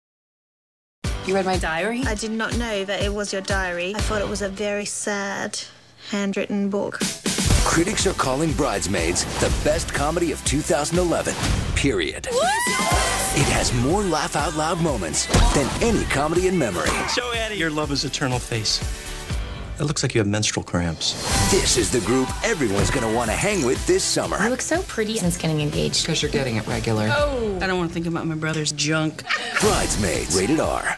TV Spots